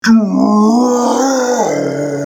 Download Scary Voice sound effect for free.
Scary Voice